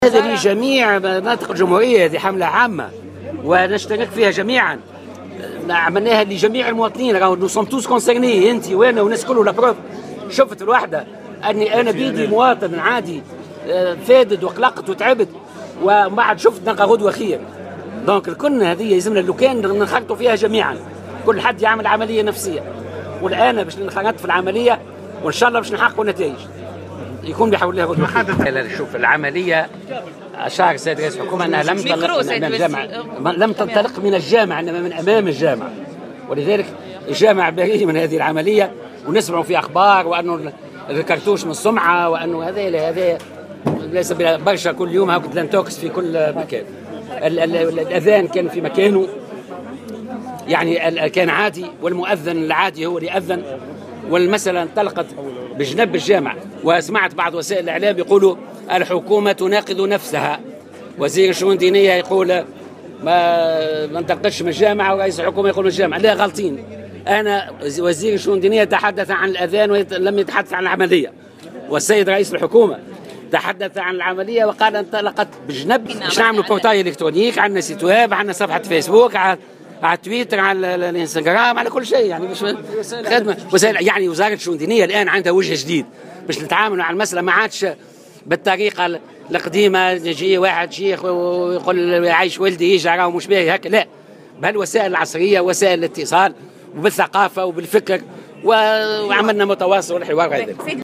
أكد وزير الشؤون الدينية محمد خليل في تصريح إعلامي اليوم الخميس 17 مارس 2016 على هامش ندوة لتقديم استراتيجيا وزارة الشؤون الدينية لمكافحة الإرهاب أن هذه الحملة ستشمل كافة مناطق الجمهورية وهي تهم الجميع دون استثناء مشددا على ضرورة أن تنخرط جميع الأطراف في هذه الحملة على الإرهاب.